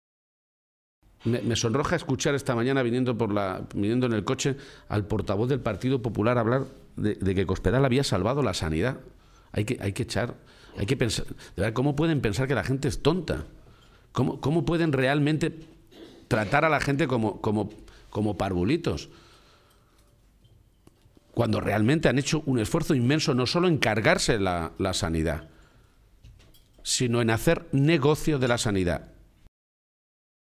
Cortes de audio de la rueda de prensa
Audio García-Page desayuno informativo en Albacete-3